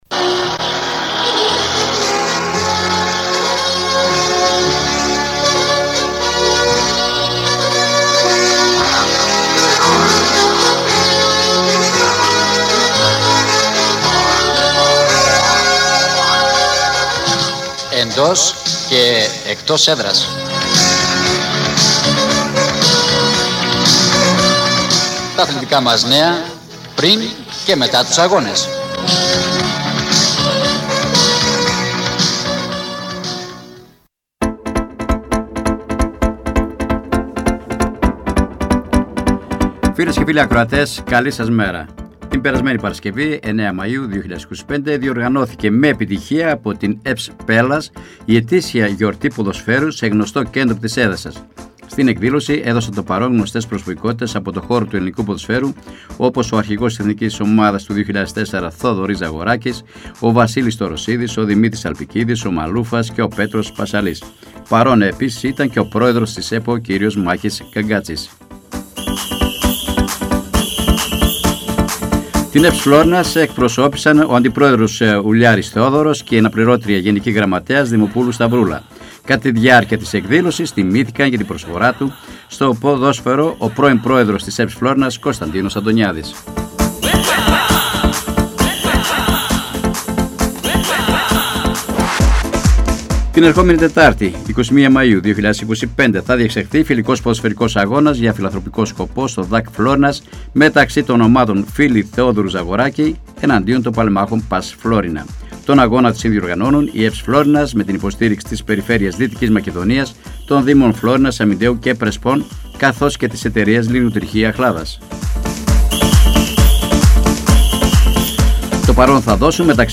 “Εντός και Εκτός Έδρας” Εβδομαδιαία αθλητική εκπομπή με συνεντεύξεις και ρεπορτάζ της επικαιρότητας, στην περιφερειακή Ενότητα Φλώρινας.